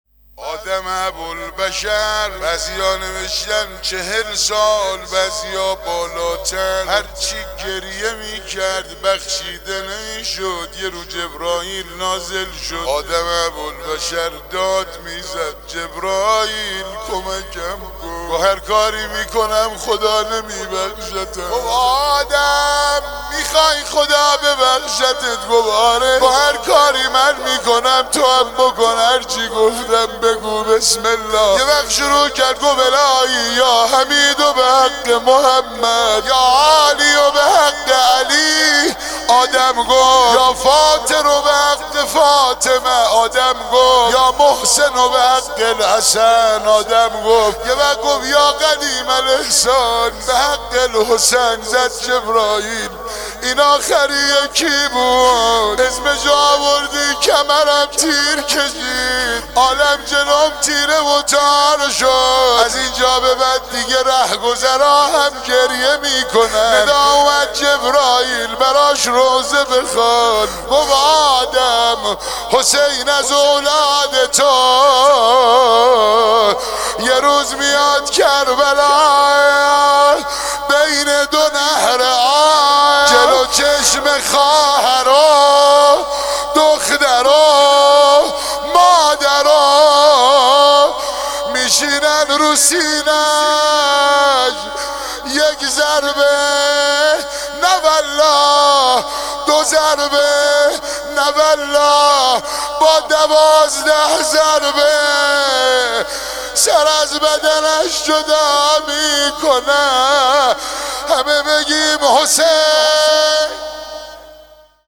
مداحی حاج حیدر خمسه |محرم 1399 | هیئت رزمندگان اسلام مرقد مطهر امام خمینی (ره)